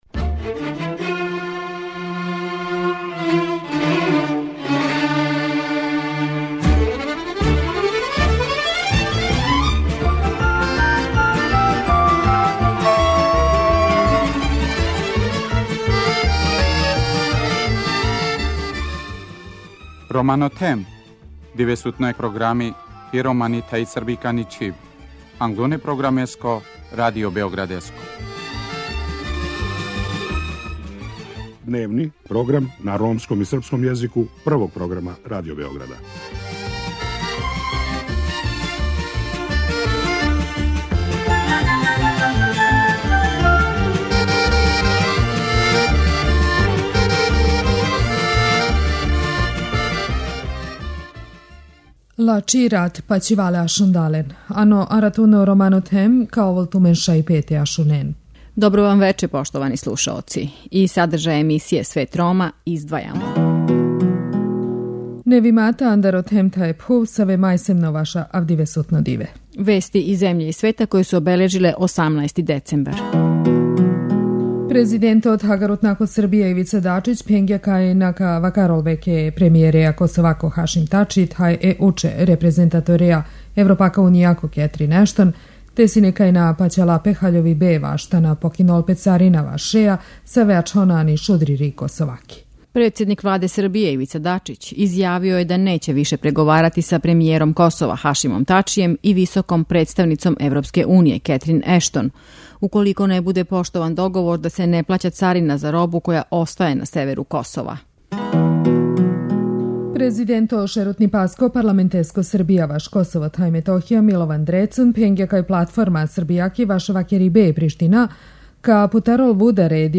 У вечерашњој емисији преносимо репортажу о животу људи који тренутно живе у највећем прихватилишту за избеглице у Берлину.